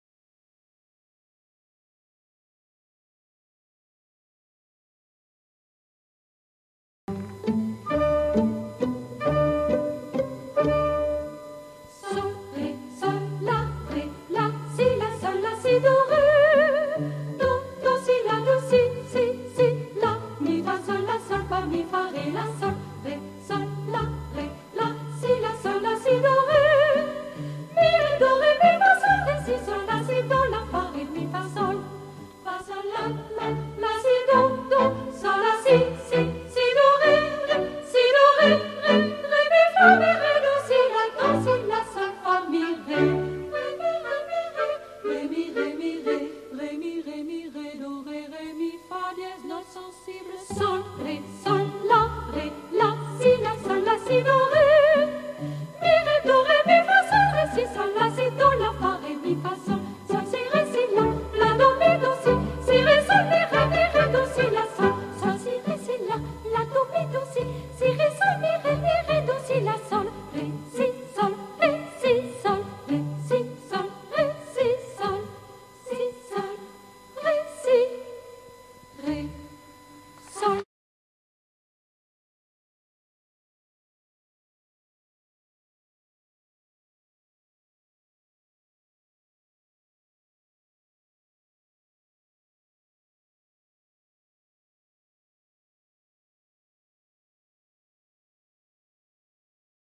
- Œuvre pour chœur à 4 voix mixtes (SATB)